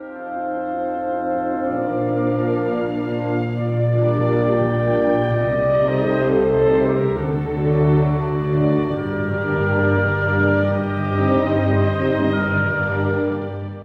↑古い録音のため聴きづらいかもしれません！（以下同様）
ハーモニーの美しさを前面に出した、穏やかな楽章です。
この楽章に関してはメロディーはもとより、整った和声進行が素敵だと感じます。